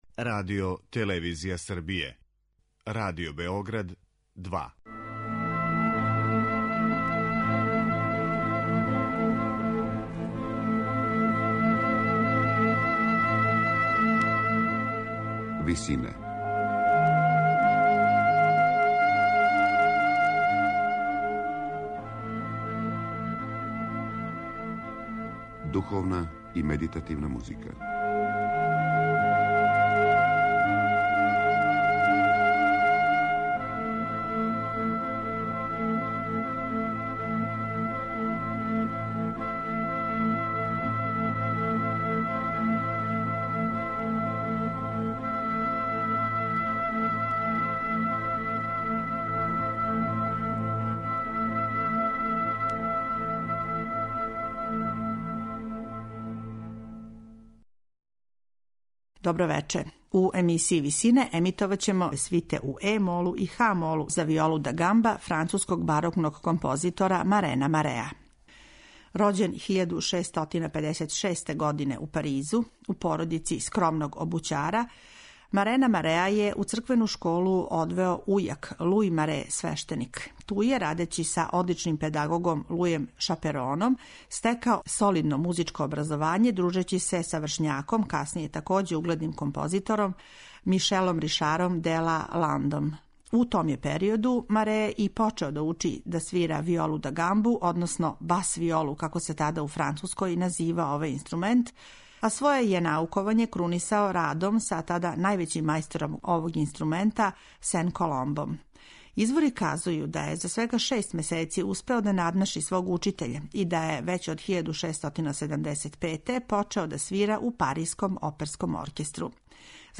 свите за бас-виолу француског барокног